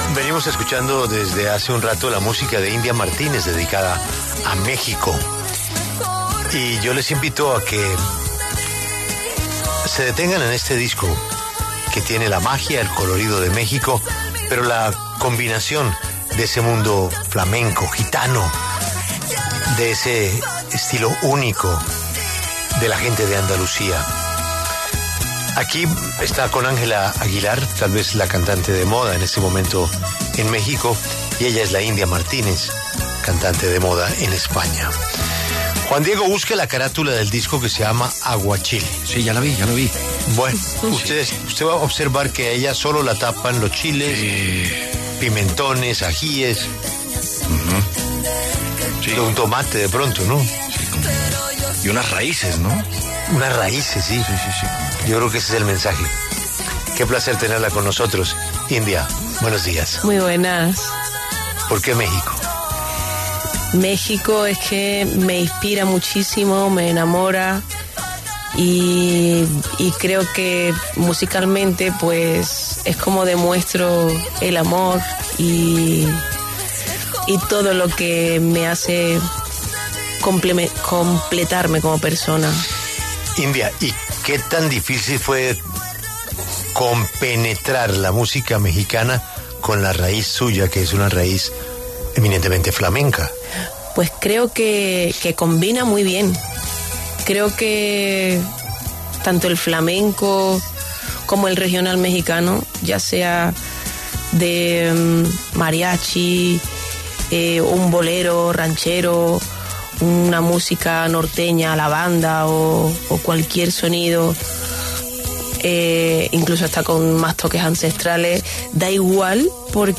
India Martínez, cantante y compositora española, nominada al Grammy Latino, pasó por los micrófonos de La W y habló sobre su nuevo álbum musical ‘Aguachile’.